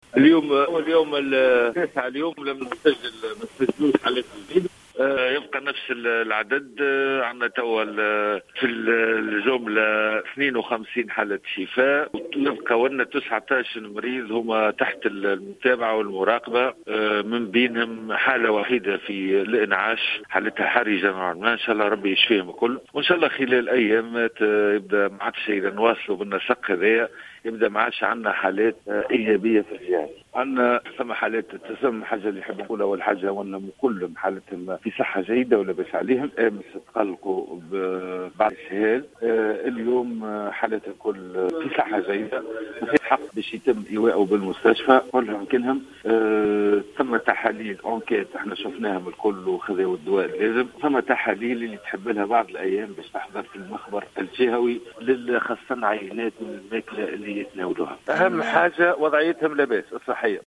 أكد المدير الجهوي للصحة بسوسة، سامي الرقيق في تصريح اليوم لـ"الجوهرة أف أم" عدم تسجيل إصابات جديدة بفيروس "كورونا" وذلك لليوم التاسع على التوالي.
المدير الجهوي للصحة بسوسة